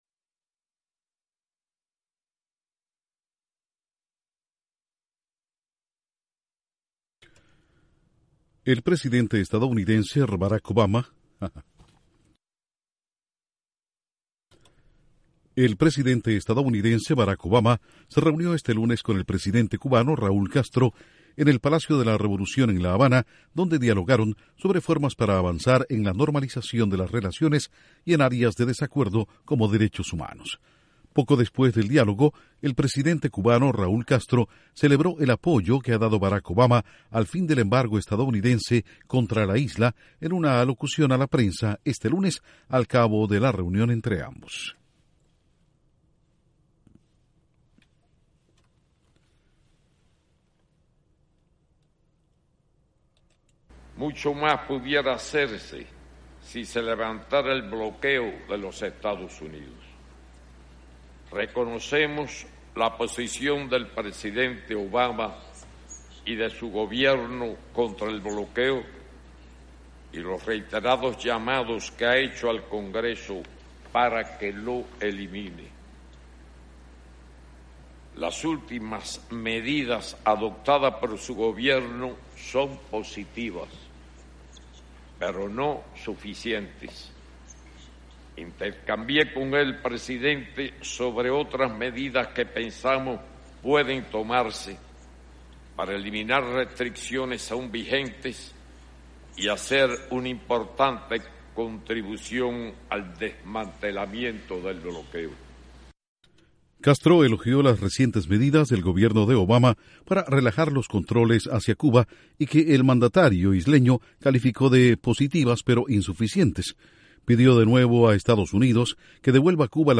Presidentes de Cuba y Estados Unidos hablan en La Habana sobre el embargo comercial a la isla y las recientes medidas de Washington.